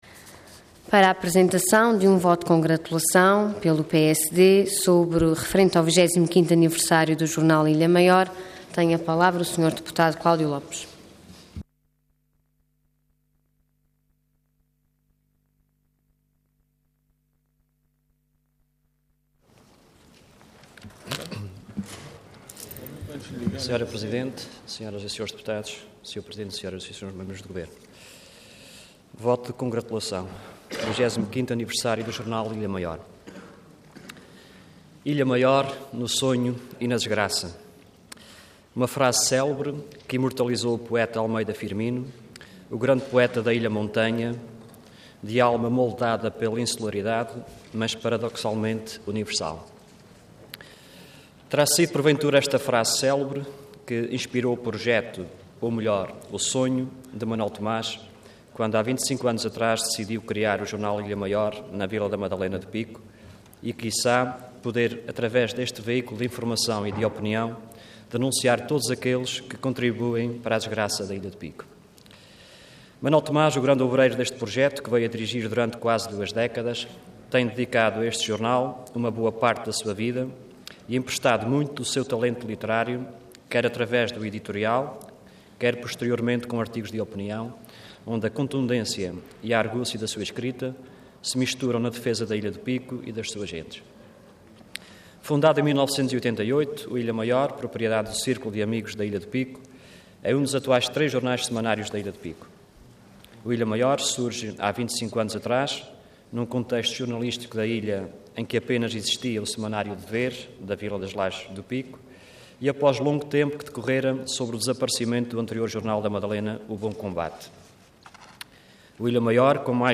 Intervenção Voto de Congratulação Orador Cláudio Lopes Cargo Deputado Entidade PSD